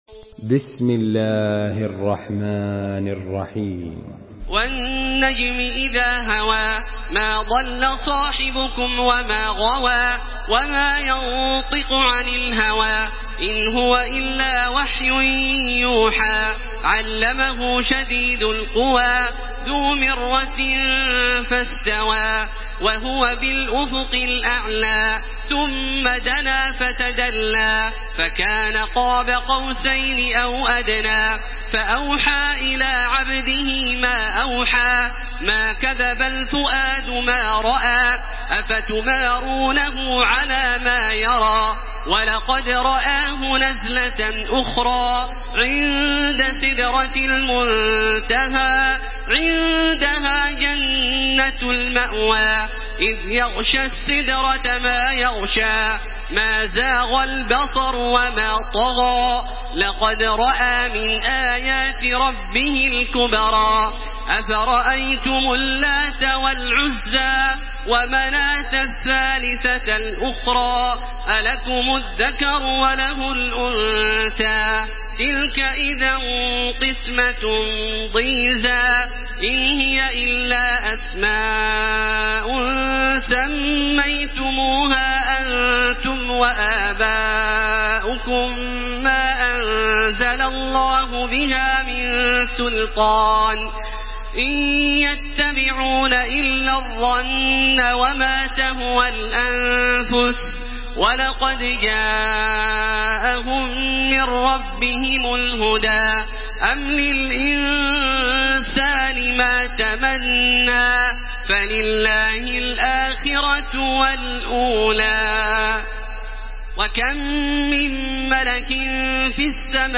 تراويح الحرم المكي 1434
مرتل